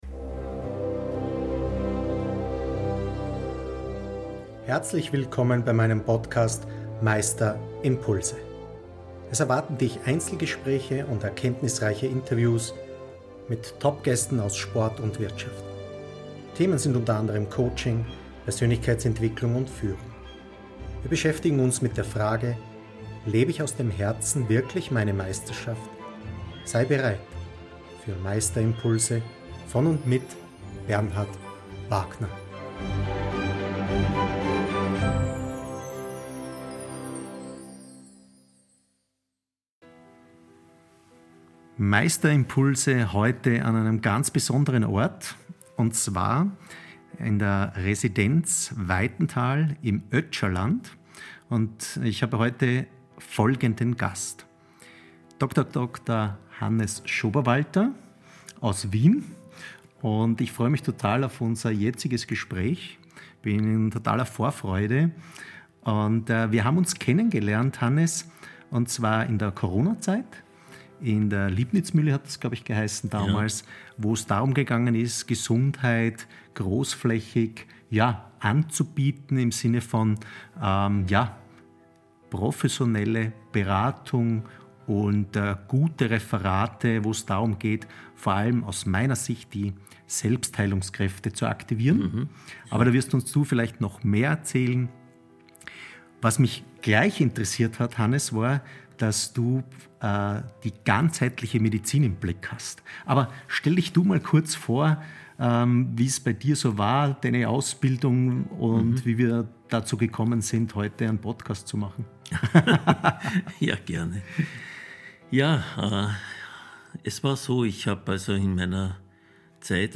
Gottesbeziehung und Führung neu gedacht Interviewgast